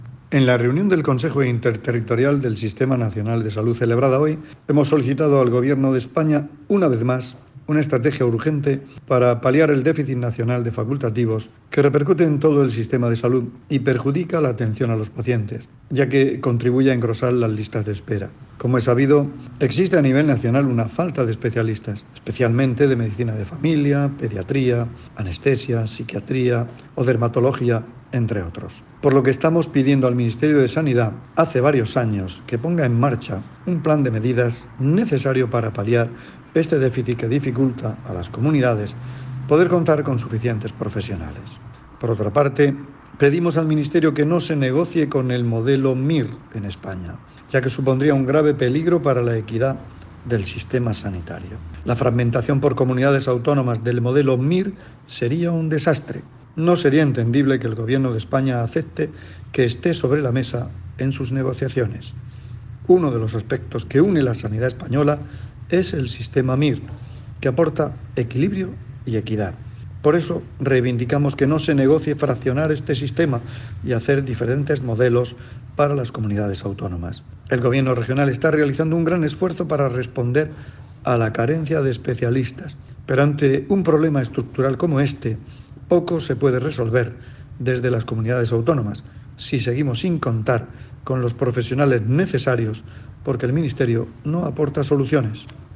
Declaraciones del consejero de Salud, Juan José Pedreño, sobre la reunión del Consejo Interterritorial celebrado hoy.